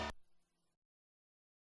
Free UI/UX sound effect: Microwave Beep.
Microwave Beep
384_microwave_beep.mp3